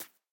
assets / minecraft / sounds / mob / rabbit / hop4.ogg
hop4.ogg